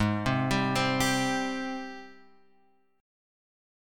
G#M7 chord {4 6 5 5 4 4} chord